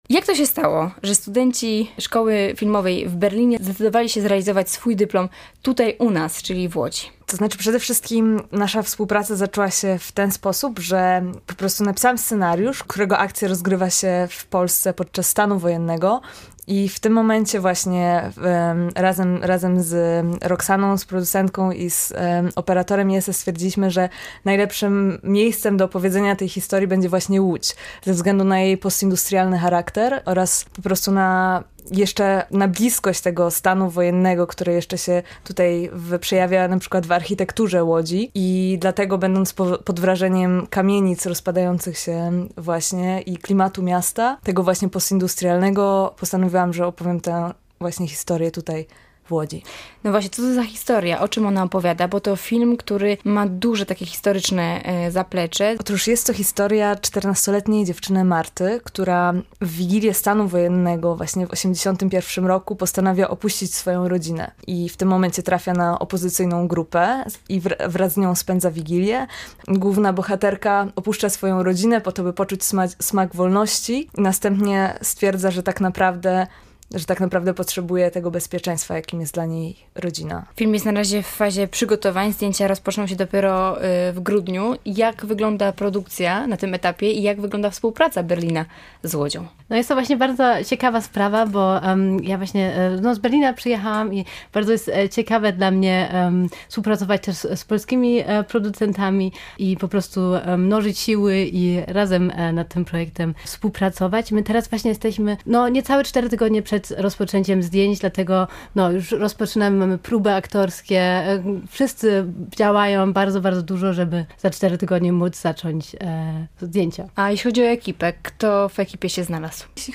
Posłuchaj całej rozmowy z realizatorami filmu i dowiedz się więcej: Nazwa Plik Autor Polsko-niemiecka współpraca.